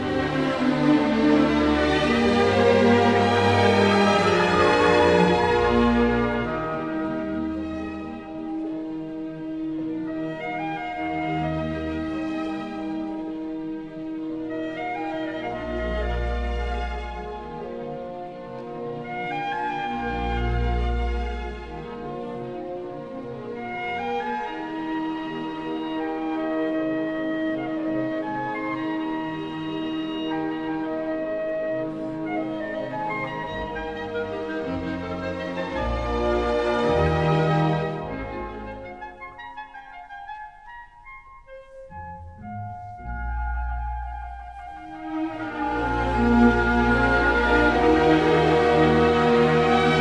Clarinet: Beethoven: Symphony No. 6 Mvt. II (mm. 68-77) – Orchestra Excerpts